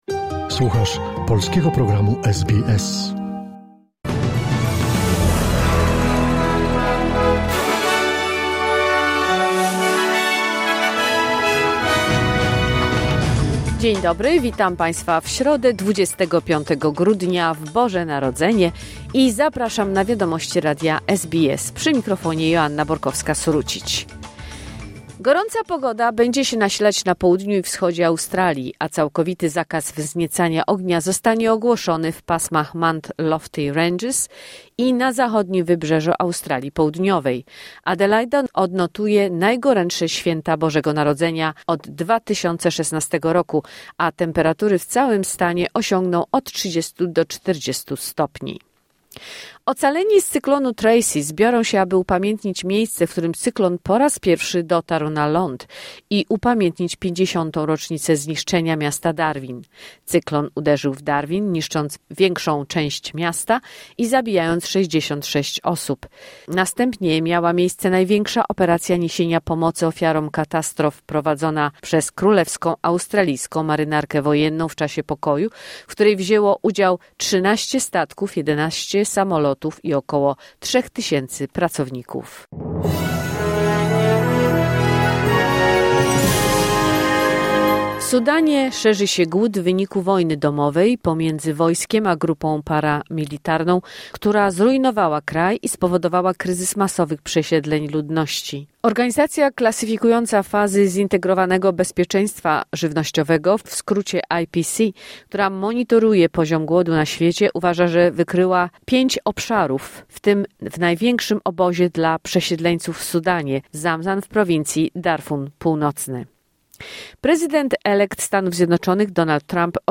Wiadomości 25 grudnia SBS News Flash